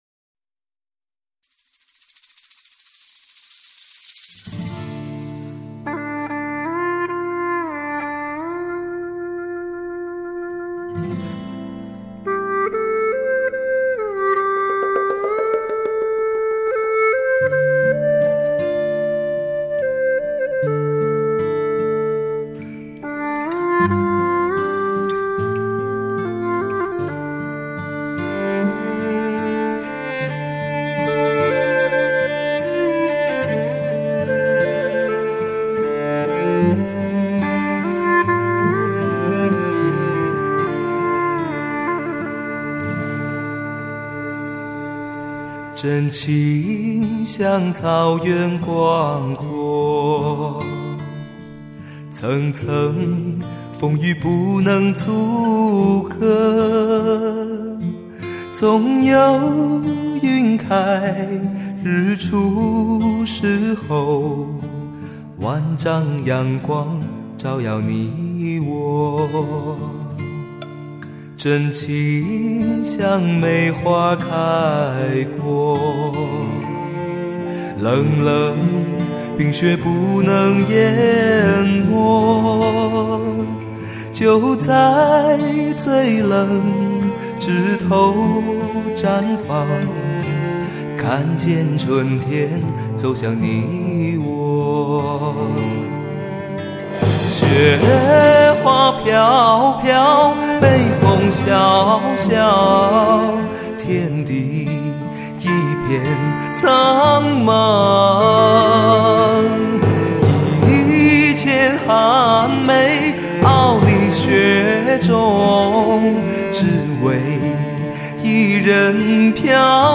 经典情歌
虽然是翻唱的歌曲，但仍有其独特的魅力，首首经典、曲曲动听。